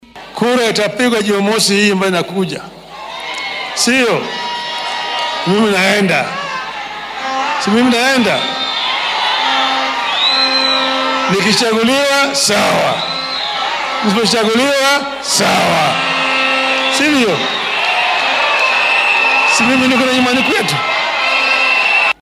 Hadalkan ayuu Raila ka jeediyay munaasabad loogu duceynayay oo ay haweenka ka tirsan xisbiga ODM ku qabteen xarunta Bomas ee magaalada Nairobi.